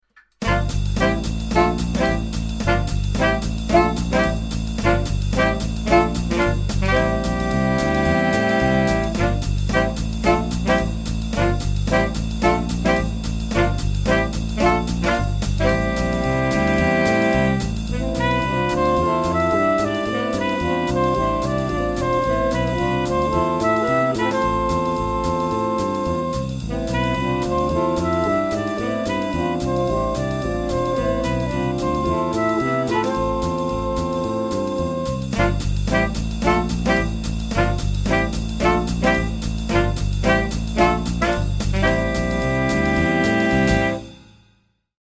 modal jazz style